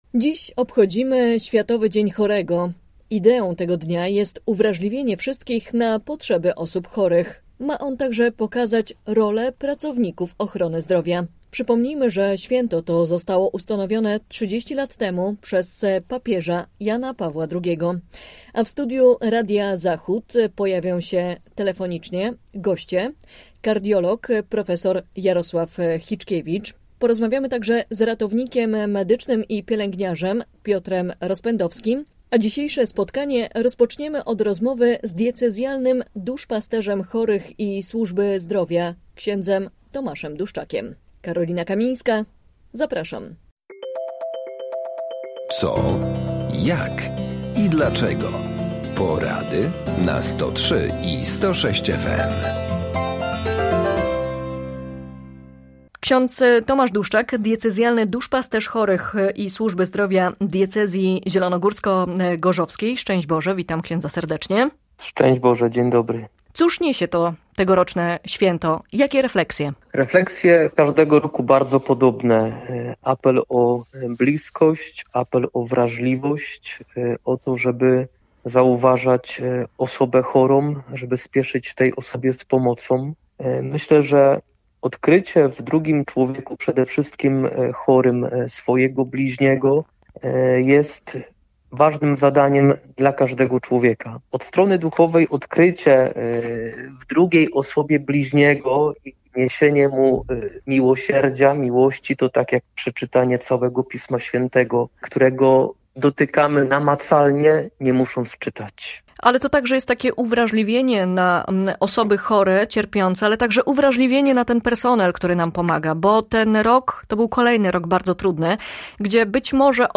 To święto, które ma na celu zwrócenie uwagi na osoby chore, jak również na te, które się nimi opiekują. Na całym świecie w obliczu pandemii korona wirusa chorzy pozostają odosobnieni od rodziny i najbliższych, a niekiedy ich jedynym wsparciem staje się personel medyczny. Goście: kardiolog, profesor